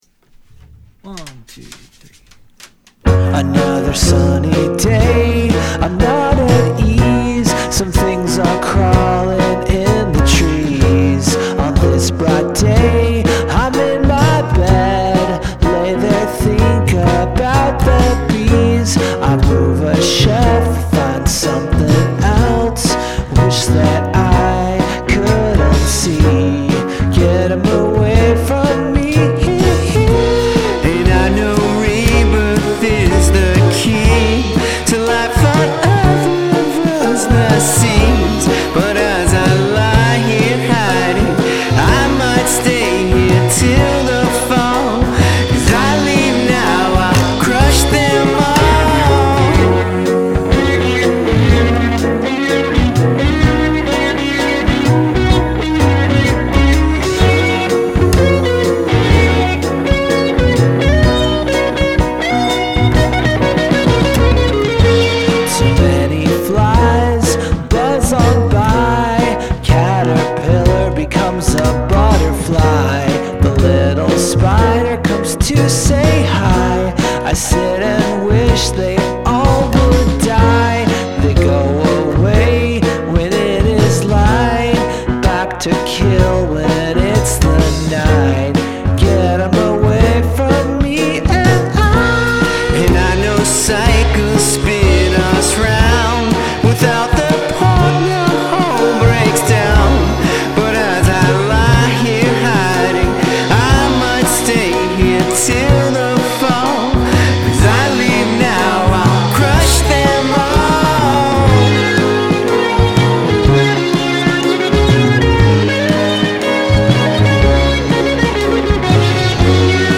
this sounds like a beatles outtake.